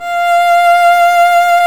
Index of /90_sSampleCDs/Roland L-CD702/VOL-1/STR_Violin 1 vb/STR_Vln1 Warm vb
STR VLN MT0J.wav